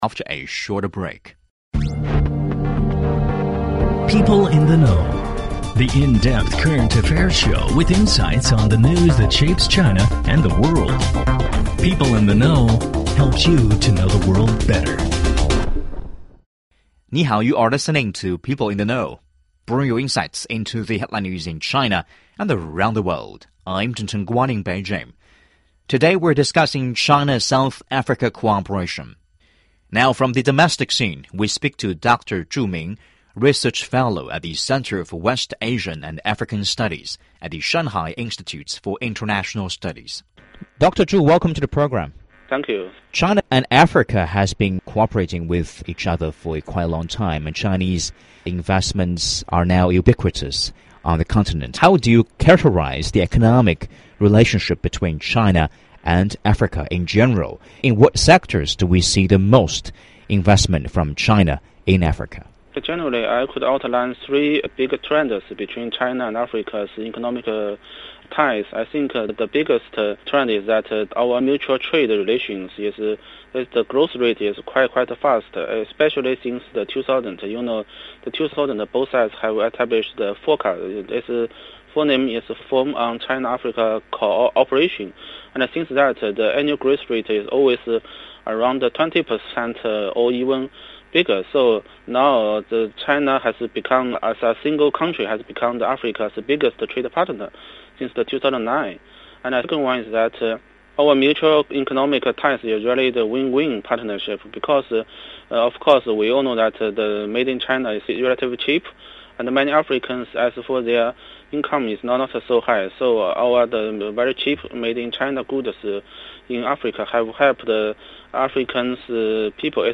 In this program, we speak to BG Magwanishe, Deputy Minister of the Department of Public Enterprise of South Africa, we caught up with the Deputy Minister when he visited China earlier this month.